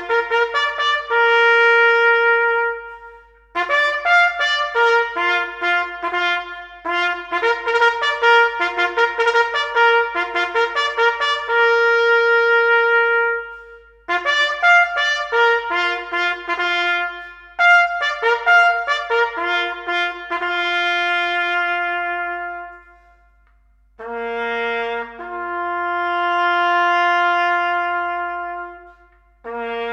• Instrumental